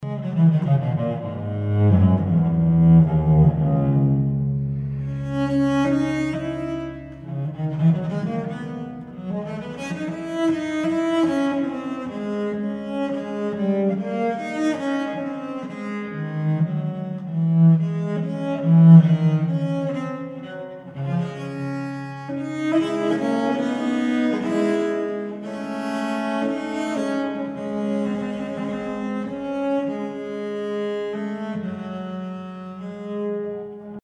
viola da gamba
Early music